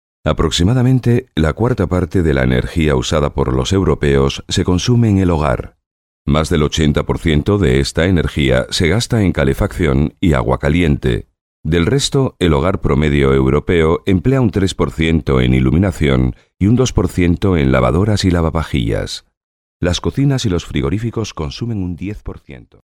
Sprechprobe: eLearning (Muttersprache):
European Spanish male voice-over, huge experience in corporate DVDs, e-learning and audiobooks.